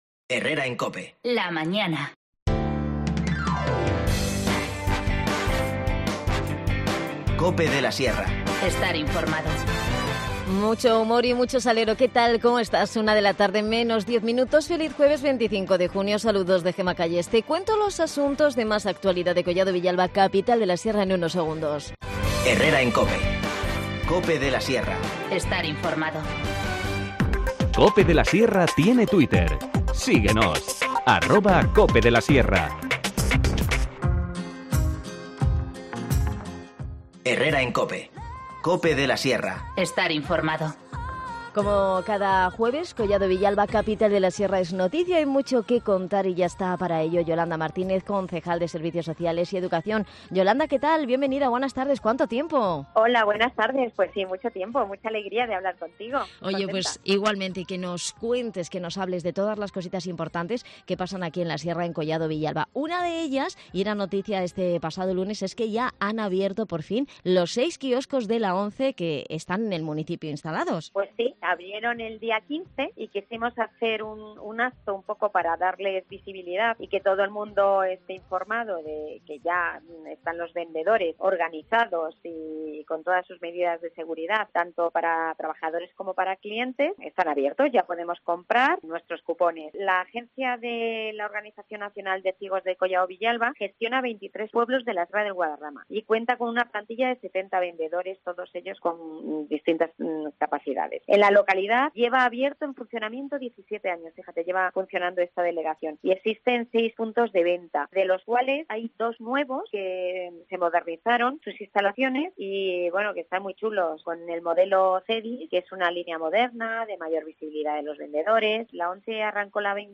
Este sábado reabren los parques infantiles en Collado Villalba. Lo ha adelantado en 'Capital de La Sierra' la segunda teniente de alcalde, Yolanda Martínez, quien también ha repasado otros asuntos de actualidad del municipio